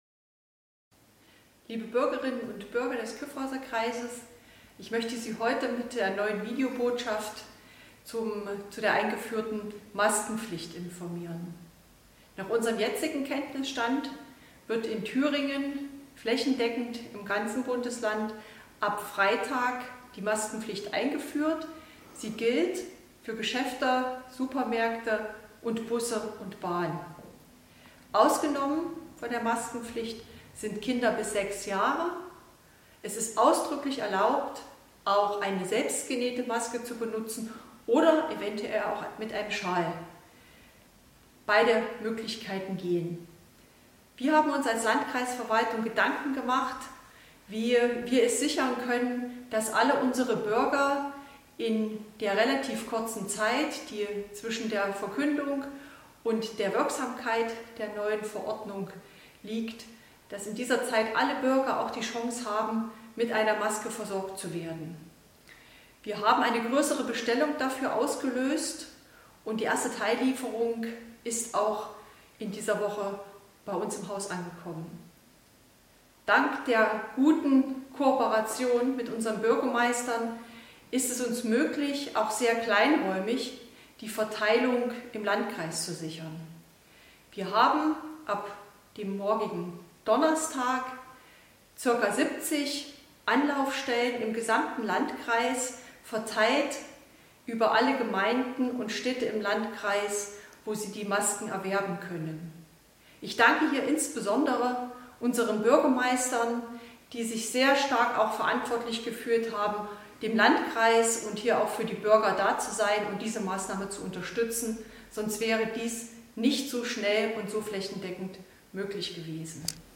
Bereits zum 11. Mal in dieser Corona-Krise wendet sich Landrätin Antje Hochwind-Schneider (SPD) an die Bürgerinnen und Bürger und informiert ausführlich über die bevorstehende Maskenpflicht.